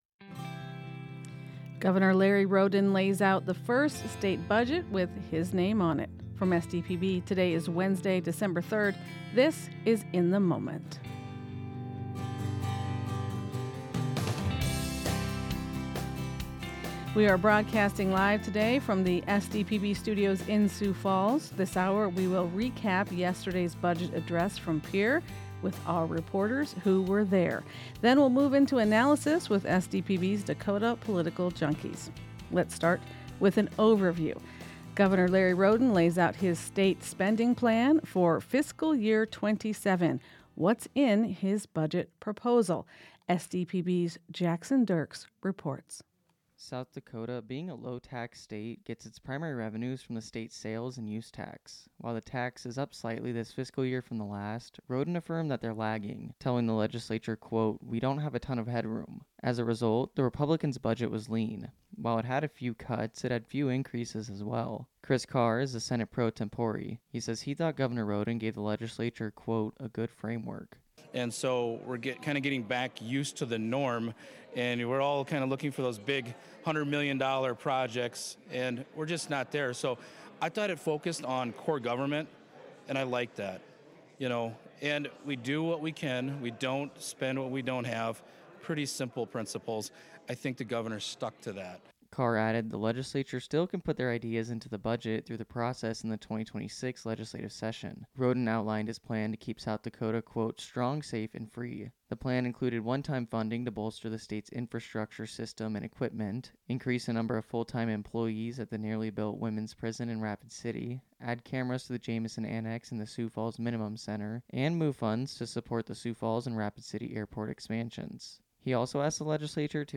in-the-moment-live-from-12-3-25.mp3